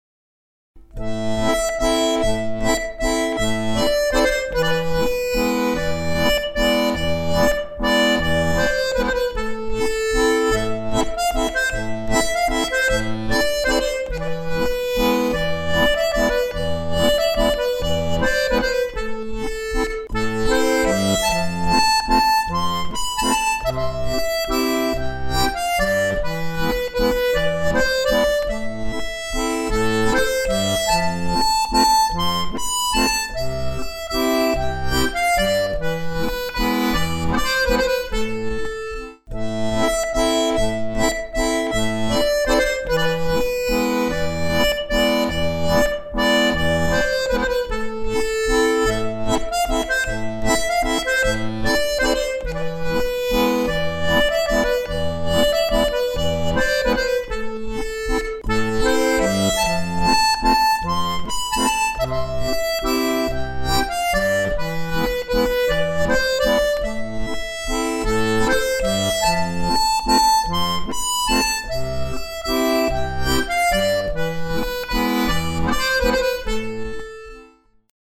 Tablatures accordéon chromatique gratuites
Vitesse normale